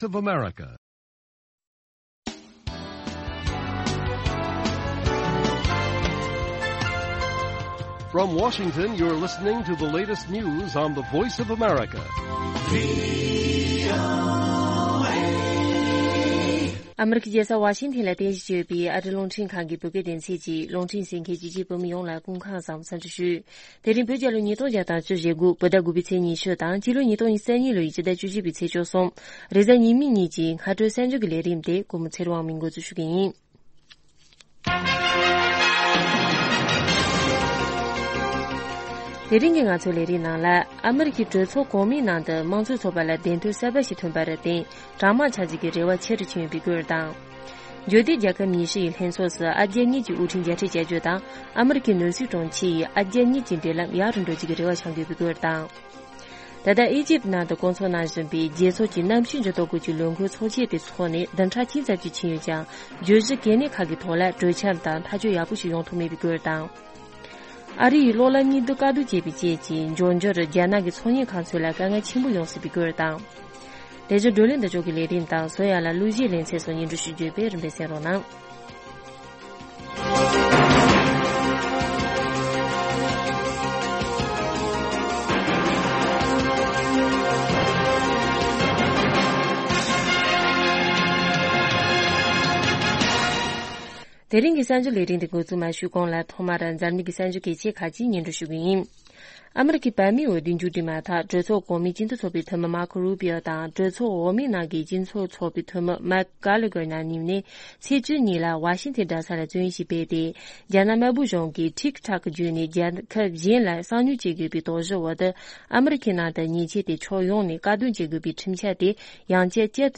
སྔ་དྲོའི་རླུང་འཕྲིན།